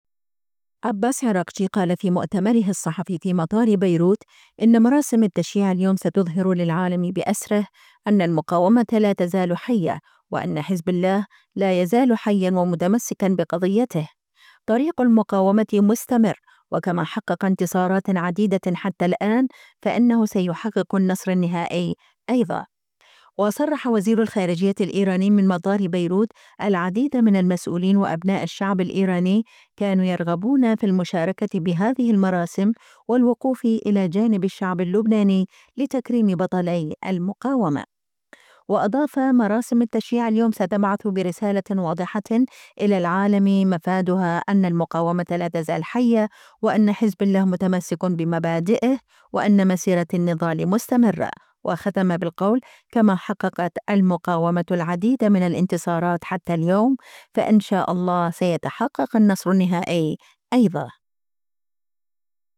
أكد عباس عراقجي، وزير الخارجية الإيراني، خلال مؤتمره الصحفي في مطار بيروت أن التشييع اليوم سيظهر للعالم بأسره أن حزب الله ما زال حياً.